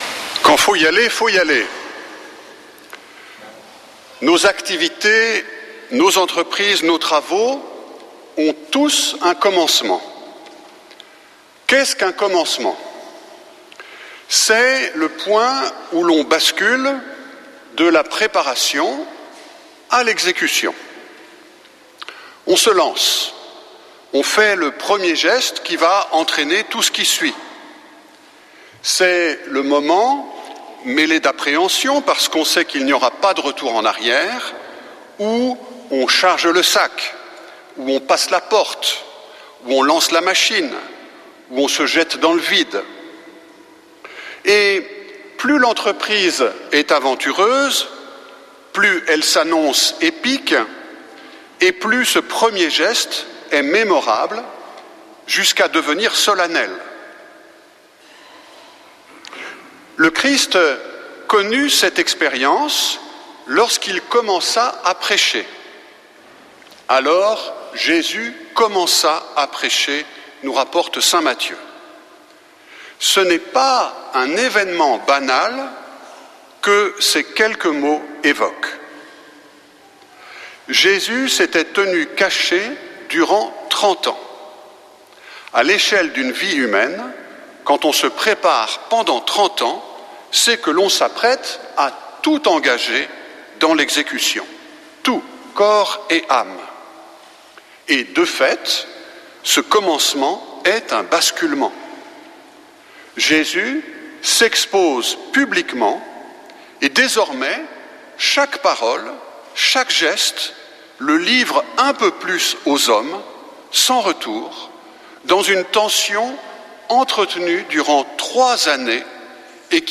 Messe depuis le couvent des Dominicains de Toulouse